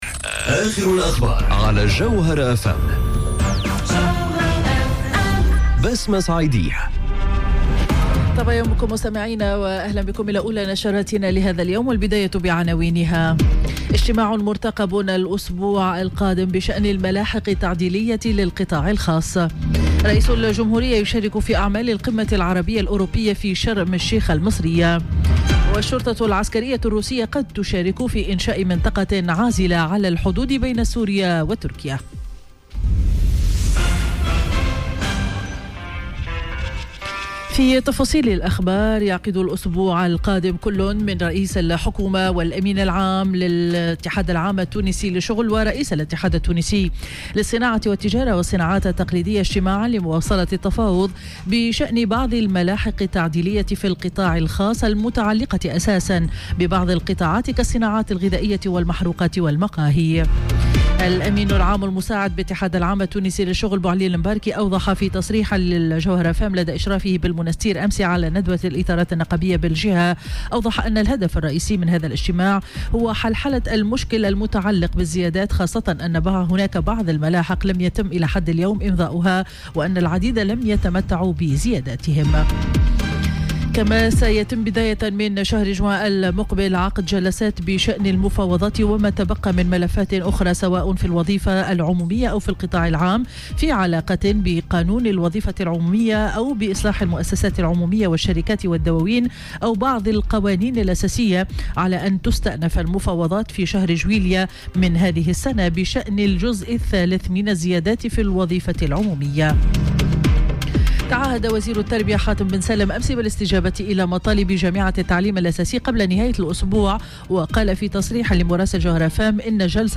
نشرة أخبار السابعة صباحا ليوم الأحد 24 فيفري 2019